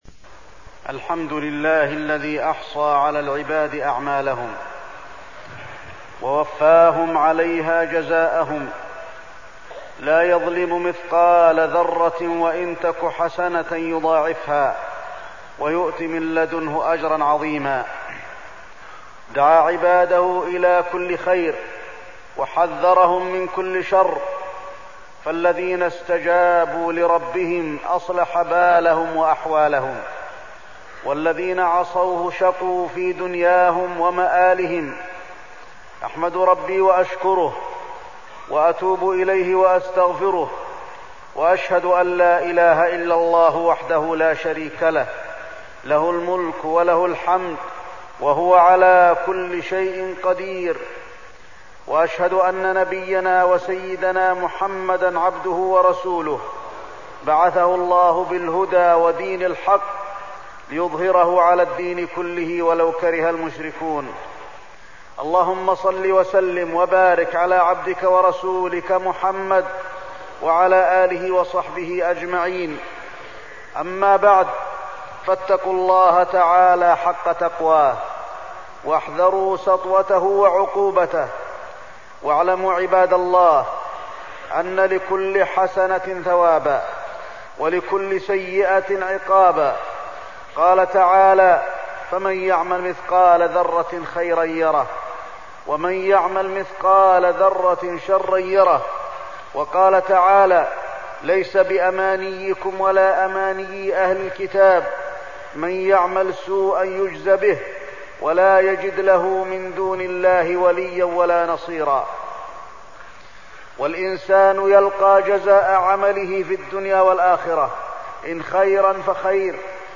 تاريخ النشر ٤ رجب ١٤١٧ هـ المكان: المسجد النبوي الشيخ: فضيلة الشيخ د. علي بن عبدالرحمن الحذيفي فضيلة الشيخ د. علي بن عبدالرحمن الحذيفي أحوال العباد بعد الموت